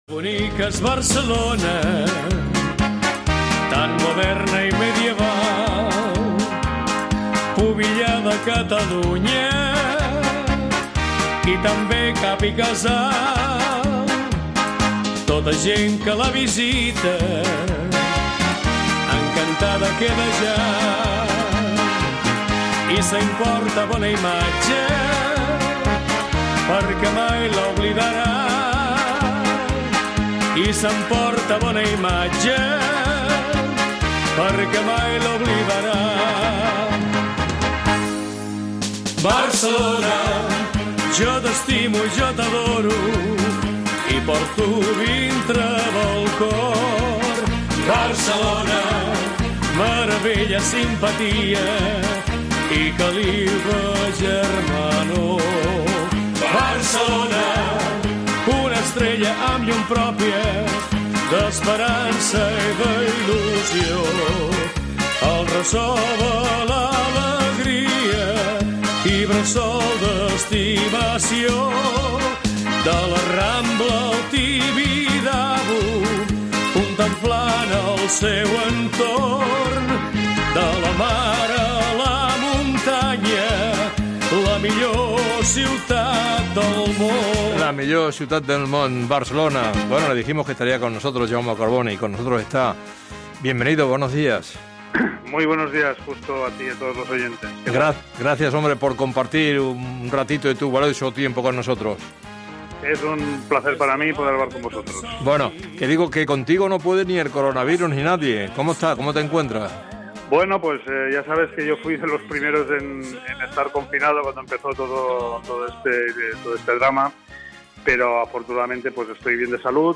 Justo Molinero entrevista a Jaume Collboni